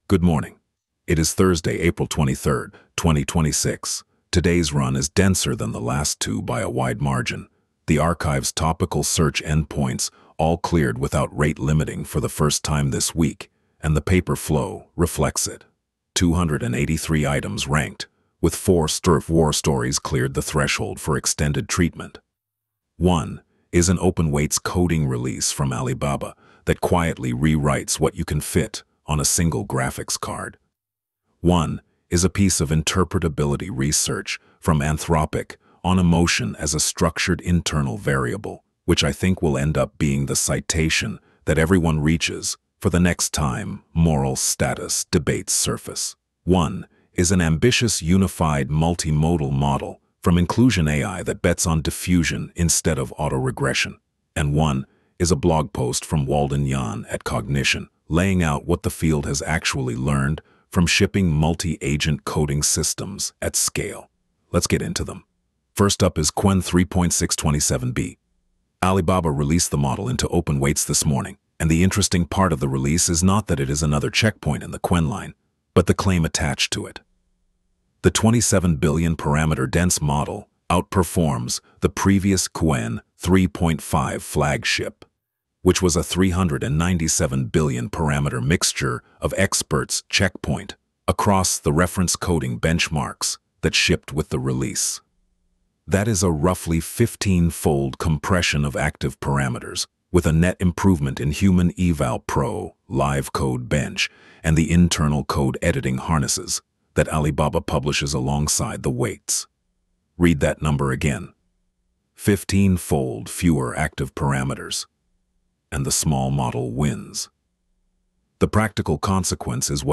Wolf Digest — 2026-04-23 Coverage window: 2026-04-22 03:39 ET → 2026-04-23 03:02 ET ▶ Press play to listen Thursday, April 23, 2026 14m 38s · top-4 narrated briefing Subscribe Apple Podcasts Spotify Download MP3